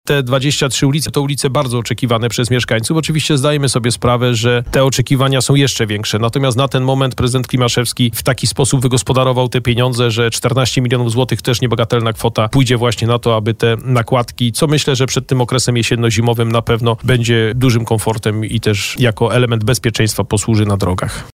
– Remont powyższych ulic jest bardzo oczekiwany przez mieszkańców. Oczywiście zdajemy sobie sprawę, że oczekiwania te są dużo większe, ale 14 milionów to też niebagatelna kwota – podkreślał rano na naszej antenie wiceprezydent Adam Ruśniak.